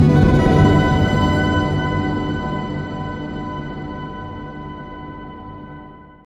SI2 WOBBL01R.wav